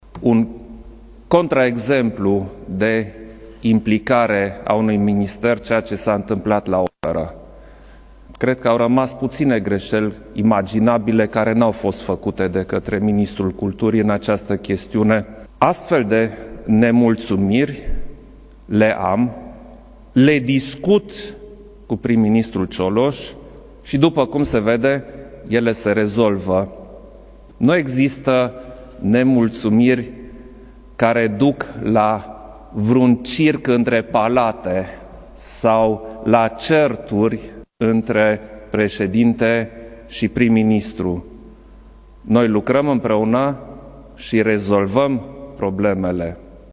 În discusul susținut cu prilejul ceremoniei de la Cotroceni, președintele Klaus Iohannis a vorbit despre motivul demisiei ministrului culturii, Vlad Alexandrescu, și anume scandalul de la Opera din București.